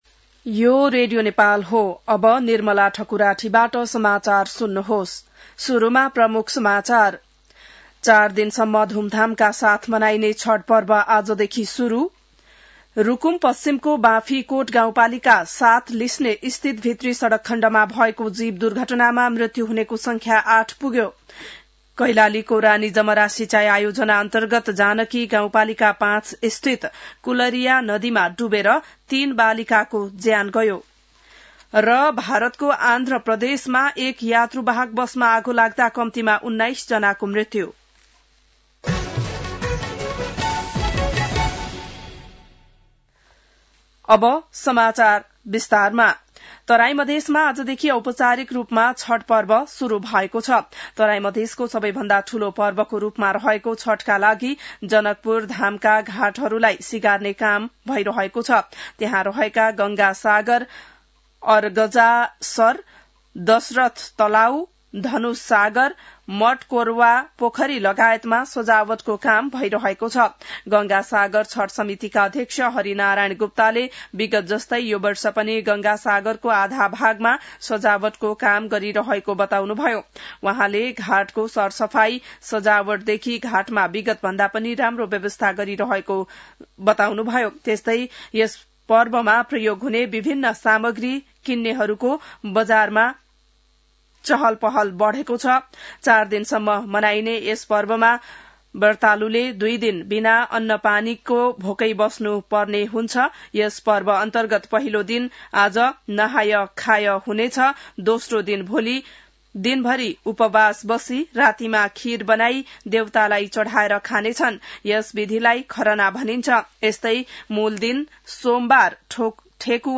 बिहान ९ बजेको नेपाली समाचार : ८ कार्तिक , २०८२